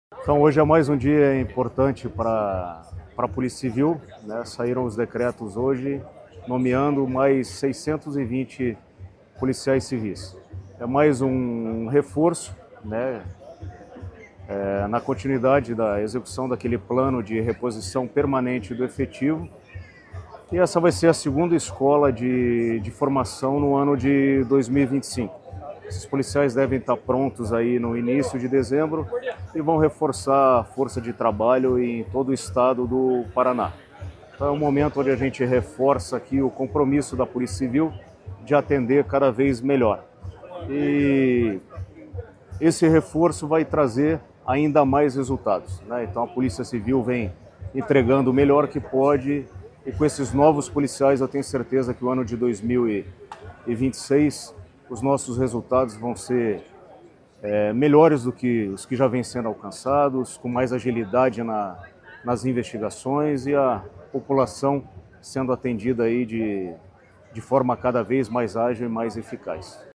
Sonora do delegado-geral da PCPR, Silvio Rockembach, sobre a nomeação de 604 policiais civis